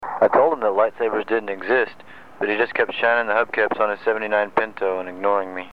light sabers